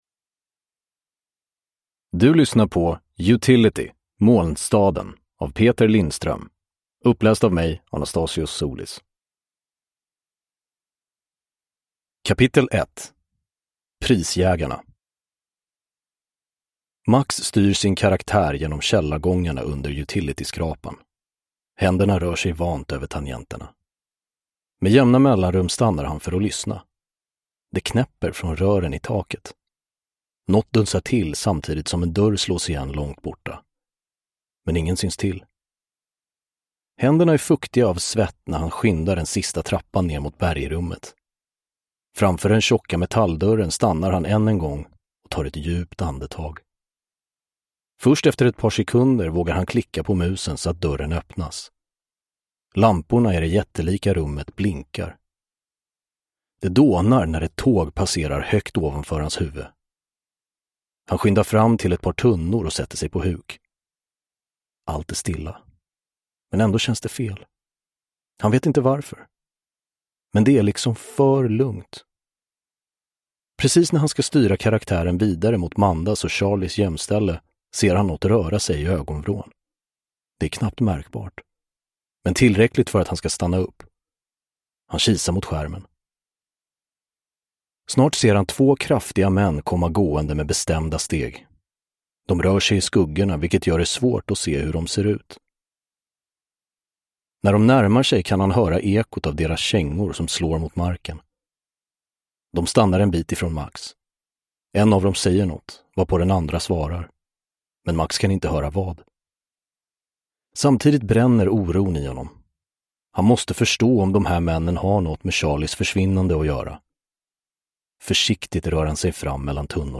Uppläsare: Anastasios Soulis